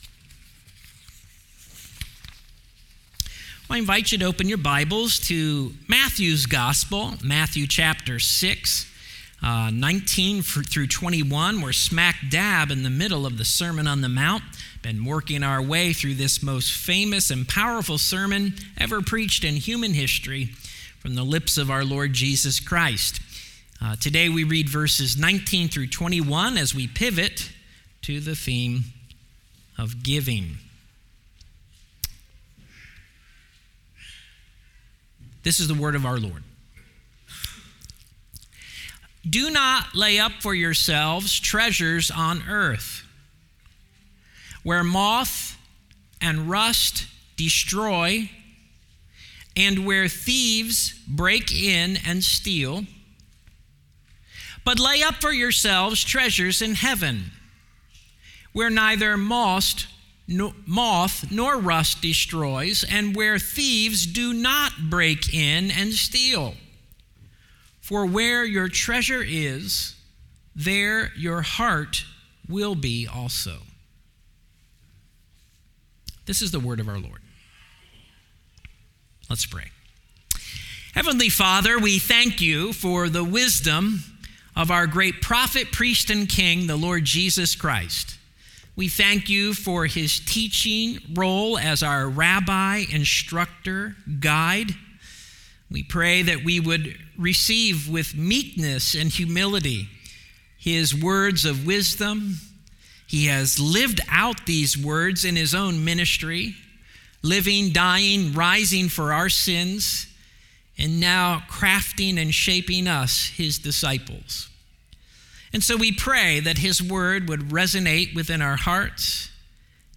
Sermons | Cedar Church